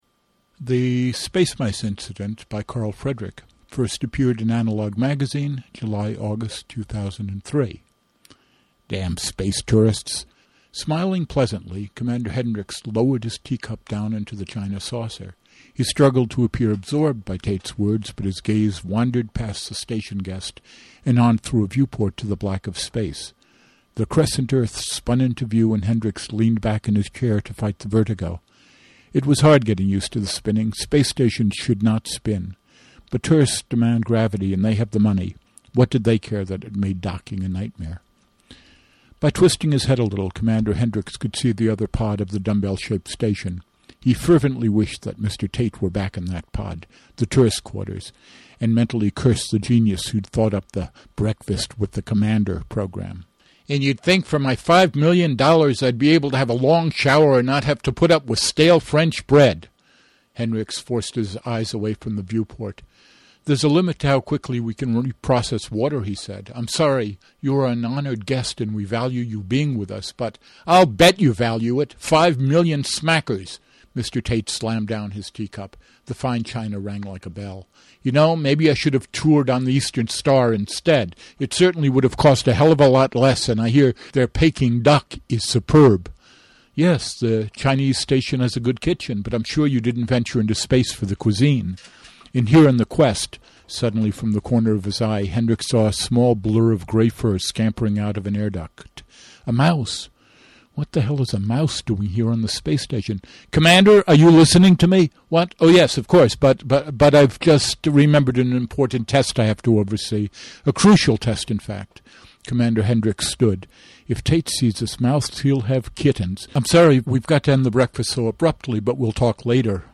The Spacemice Incident Analog Jul/Aug 2003 Length 23.5 minutes (Download 8.1 meg) [rough draft recording] An infestation of mice causes major problems on a space station.